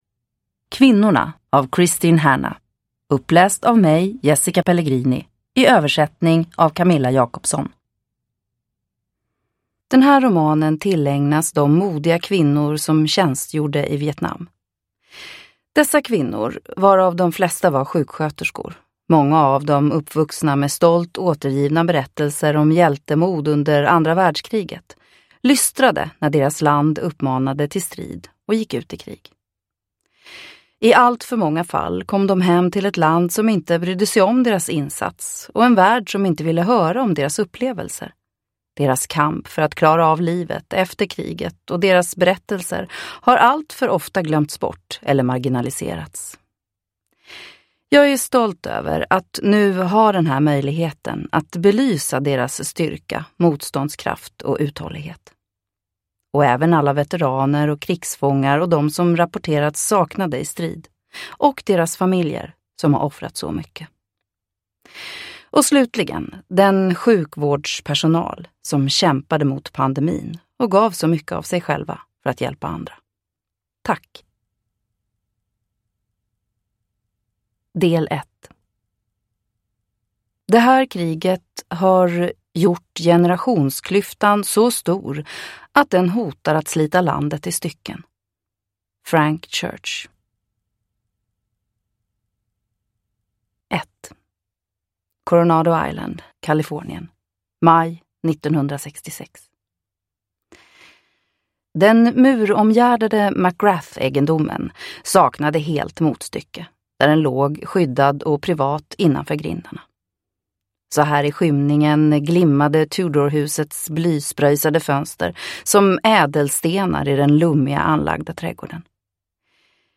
Kvinnorna (ljudbok) av Kristin Hannah